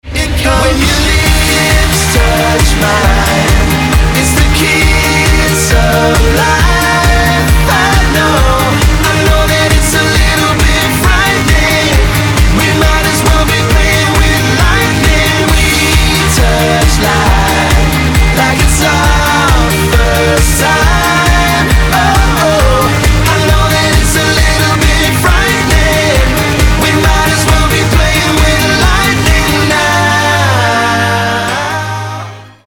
Anglicko-írska chlapčenská skupina